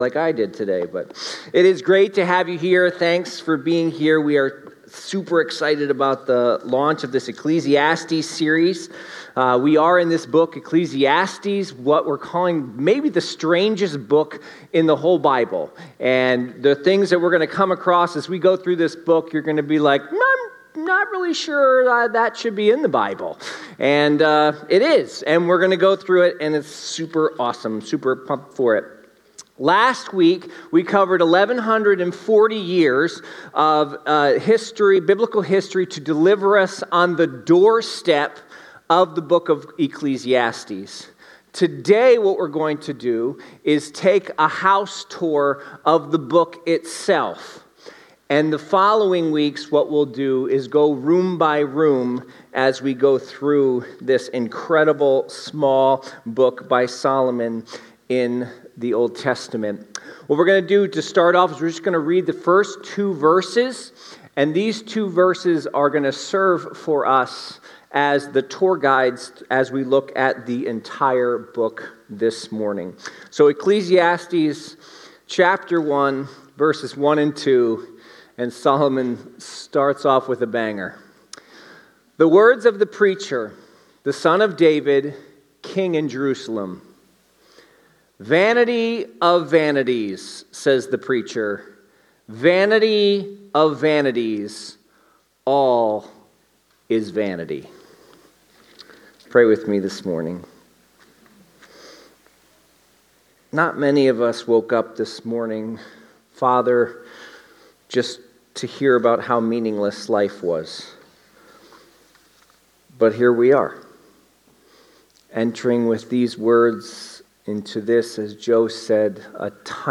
The sermon concludes with an emphasis on finding satisfaction through God, citing Psalms for further reflection.